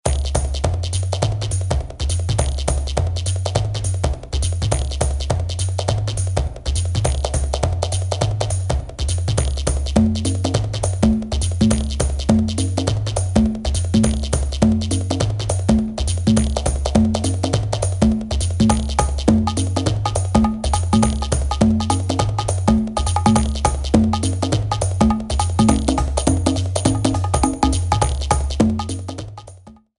minigame music rearranged